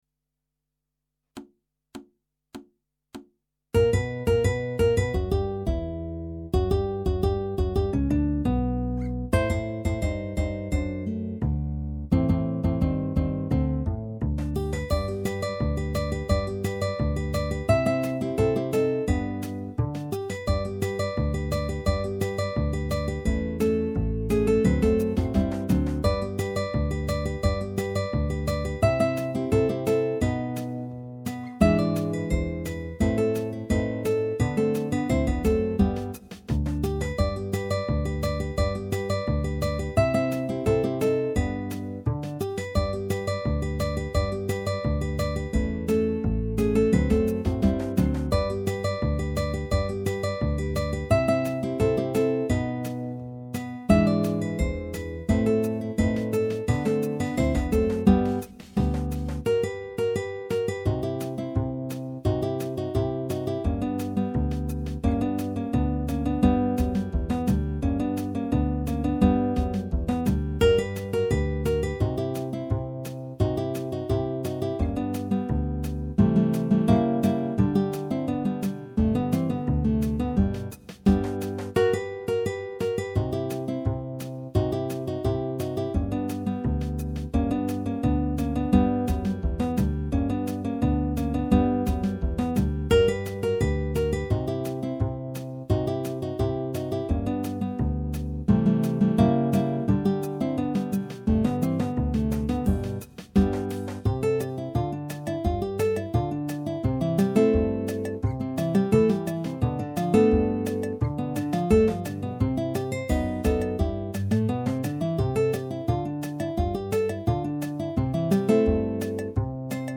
minus Guitar 3